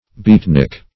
beatnik \beat"nik\ n.